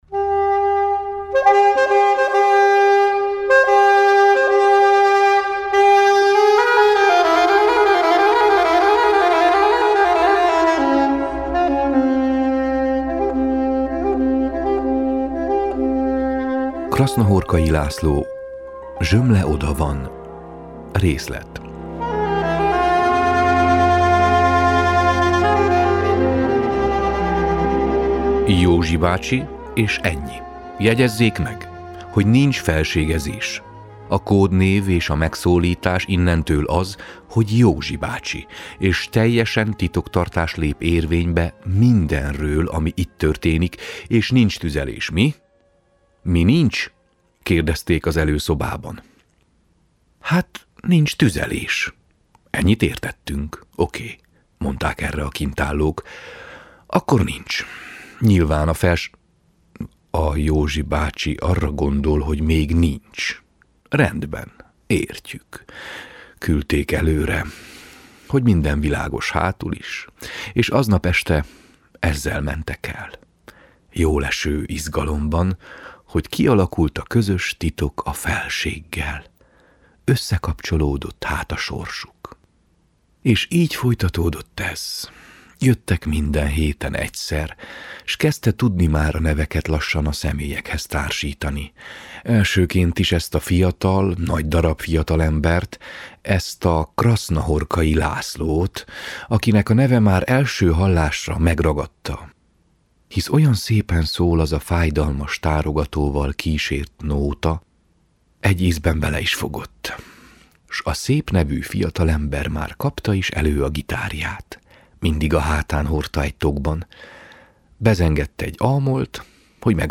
tárogató szóló